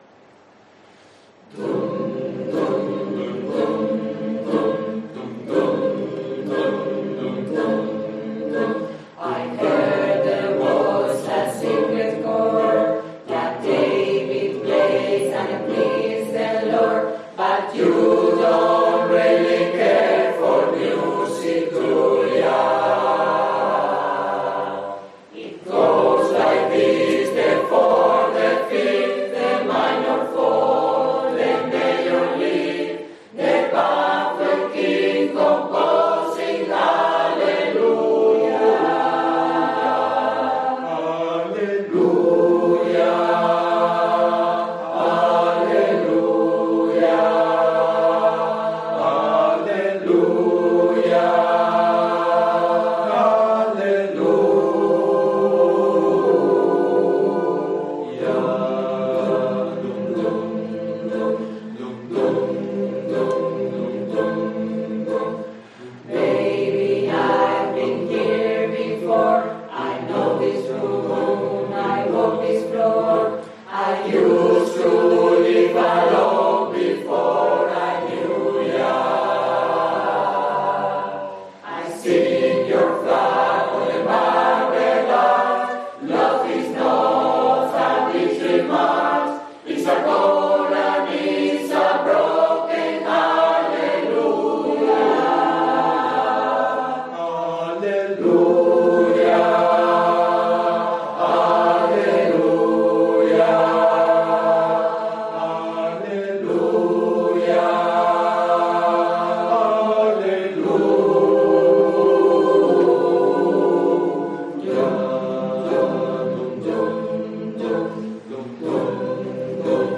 CORO DE 30 PROFESIONALES
UNA PISTA DE AUDIO AMATEUR